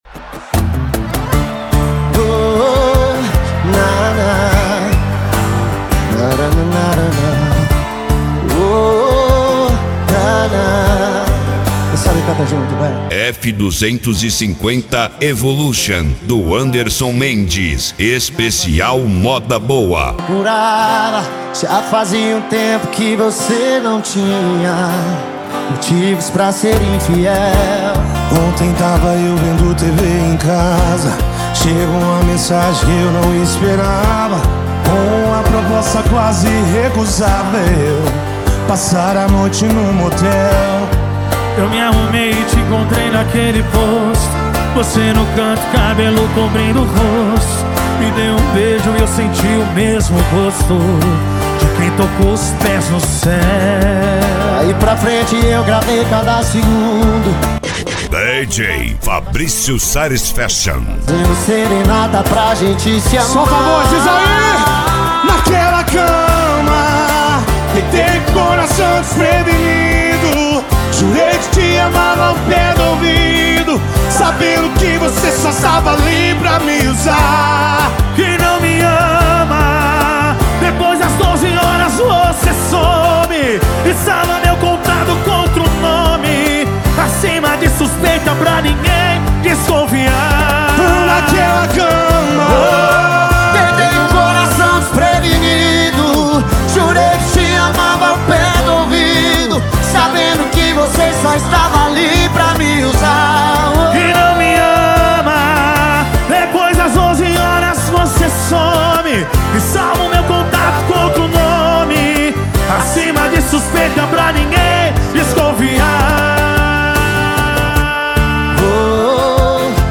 Modao
SERTANEJO